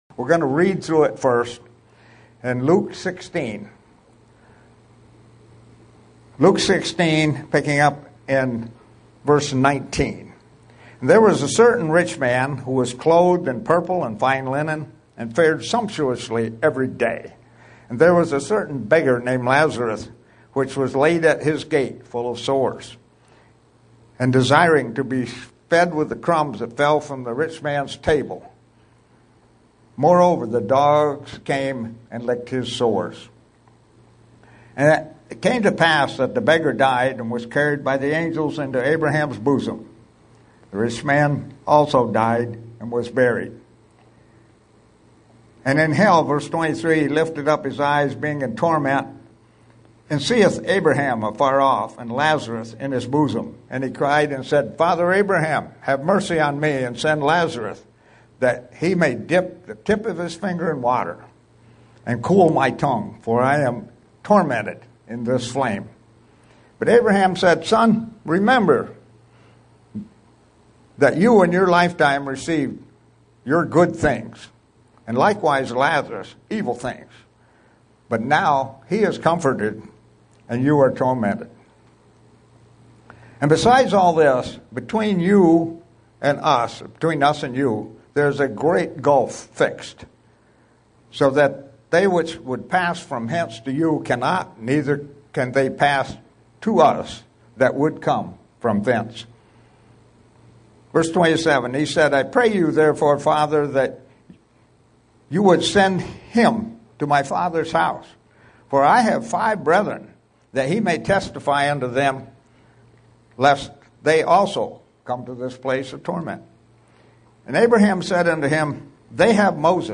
Print Another understanding of this Parable SEE VIDEO BELOW UCG Sermon Studying the bible?
Given in Buffalo, NY